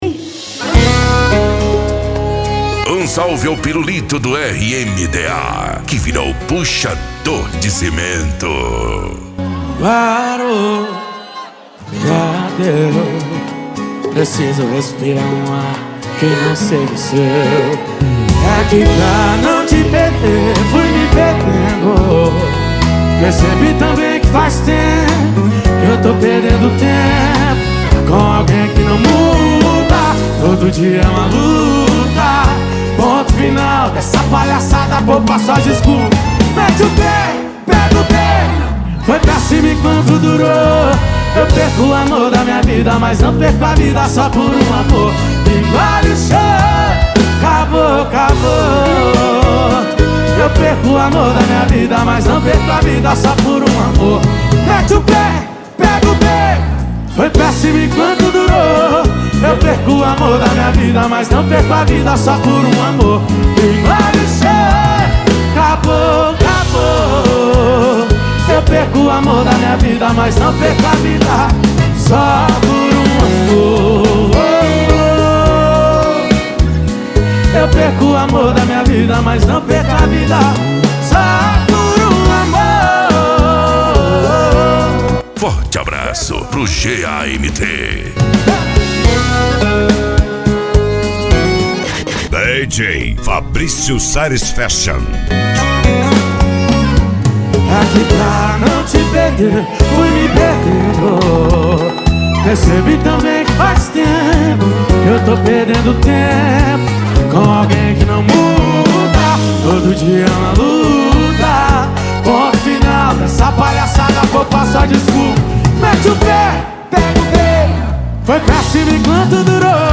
Funk
SERTANEJO